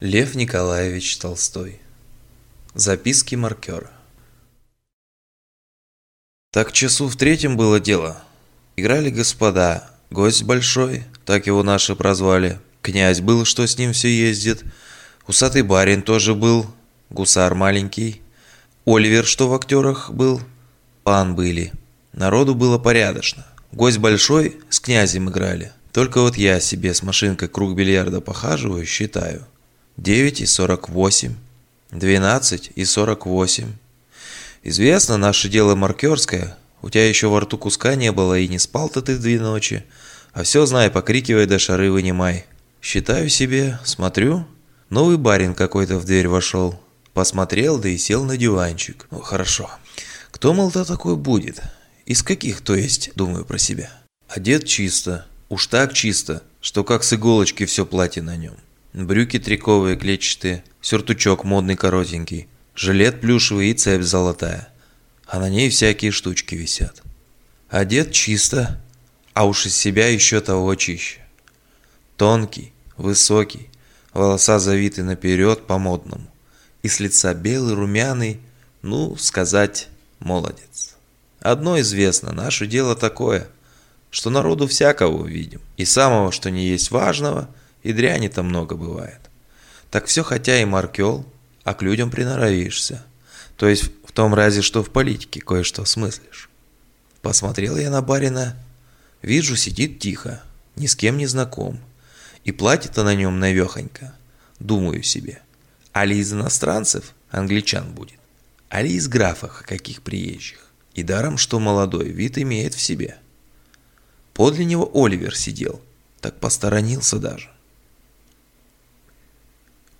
Аудиокнига Записки маркёра | Библиотека аудиокниг